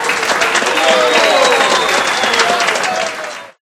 Applause2.ogg